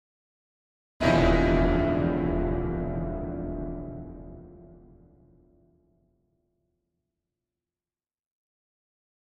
Piano Danger Chord Type A